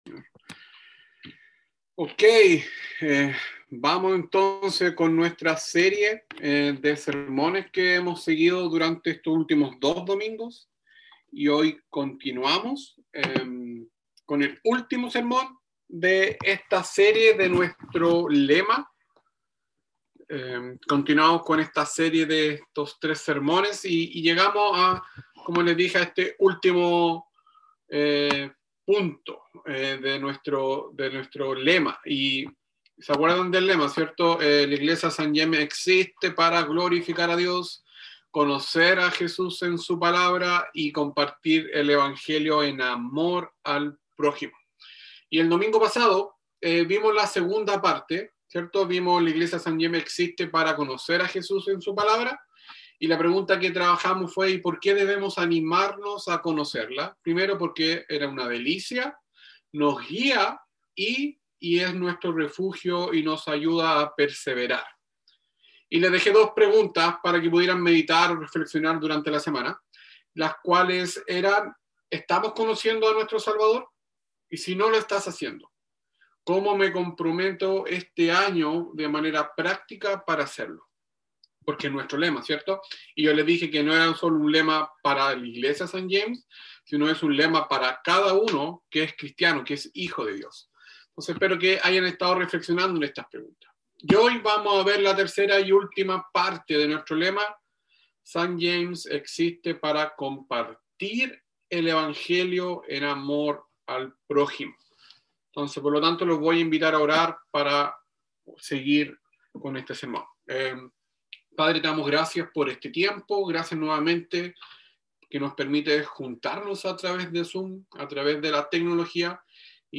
Sermones
Website de la Iglesia St. James de Punta Arenas Chile